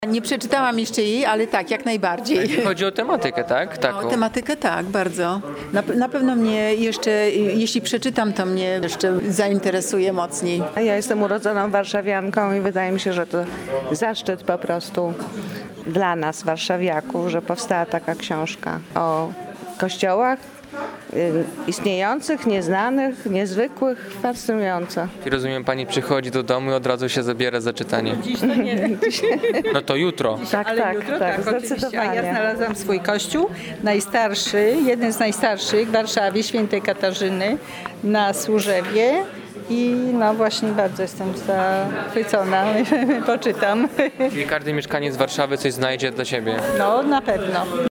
Wypowiedź uczestników spotkania: